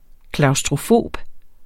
Udtale [ klɑwsdʁoˈfoˀb ]